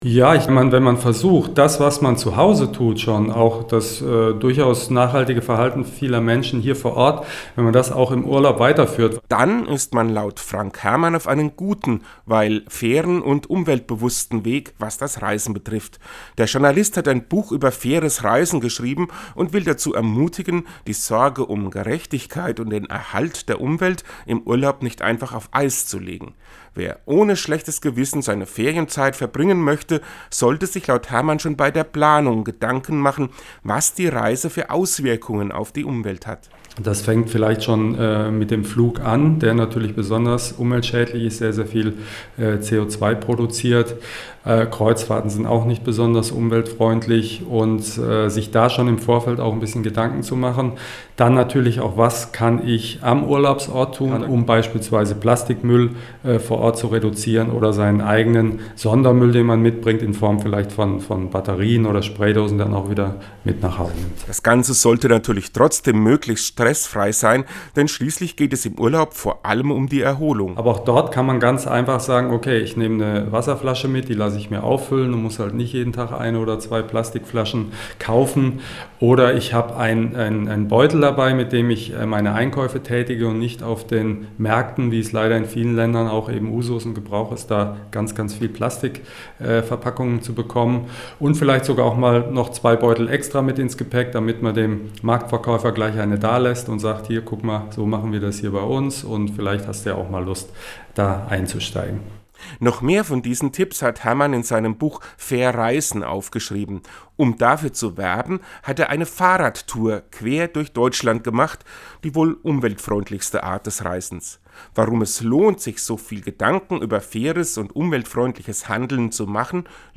Den Radiobeitrag finden Sie unten als Download!